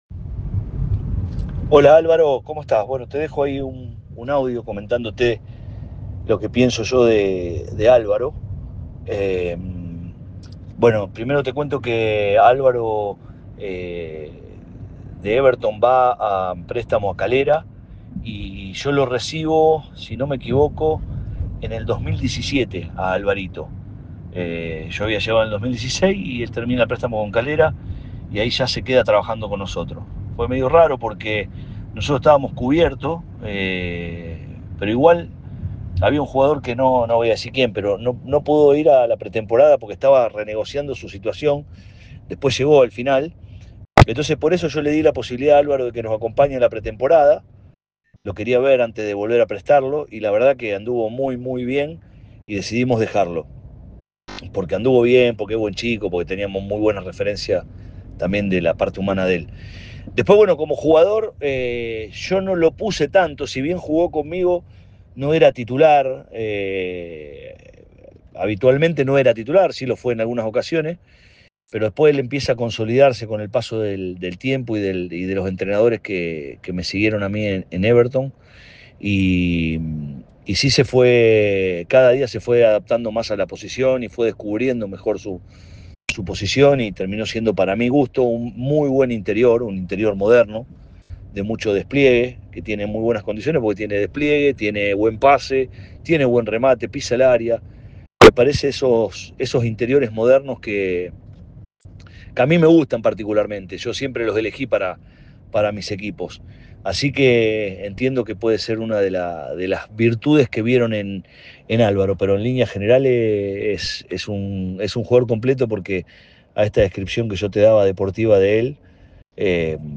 En conversación con ADN Deportes